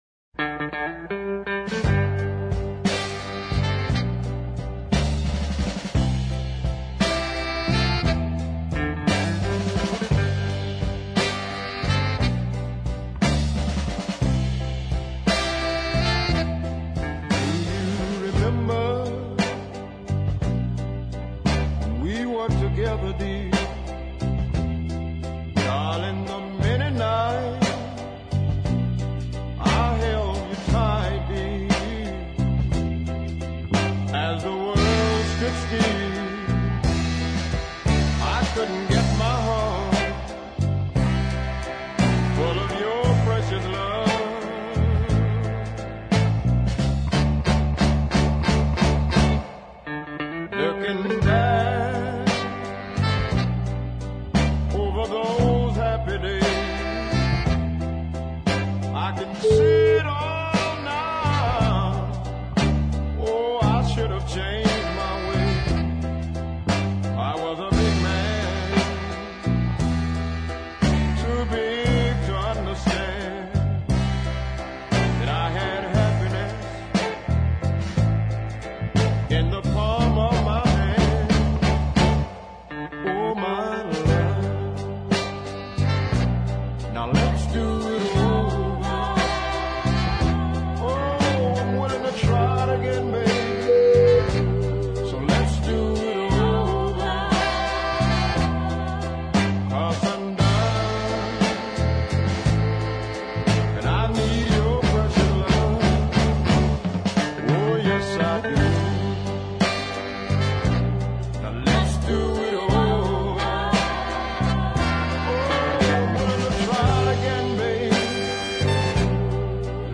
rich, dark baritone voice